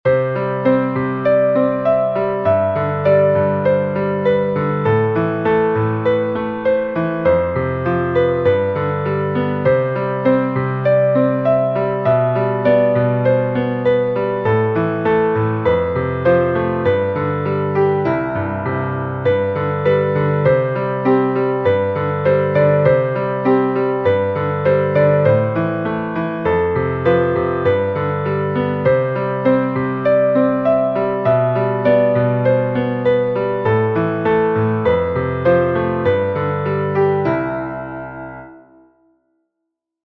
Tonalità: re maggiore
Metro: 6/8
in una versione facile per glockenspiel